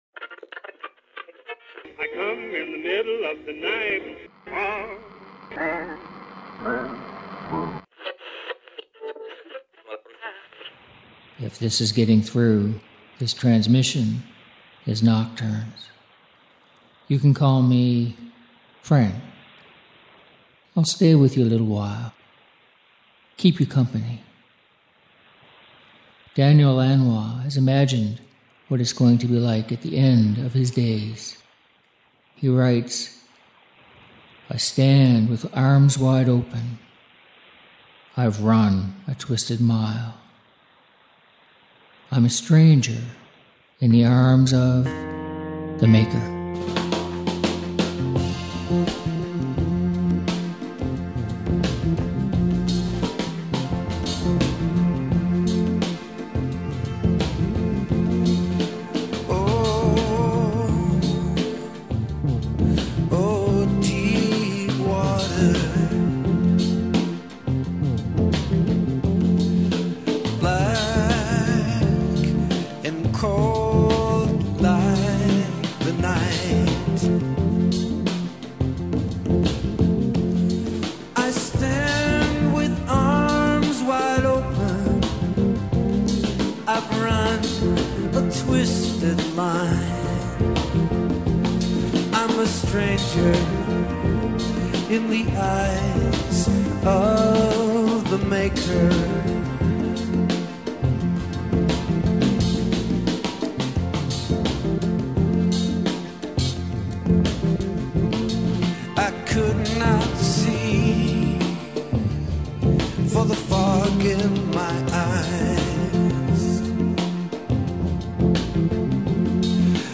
Music for nighttime listening.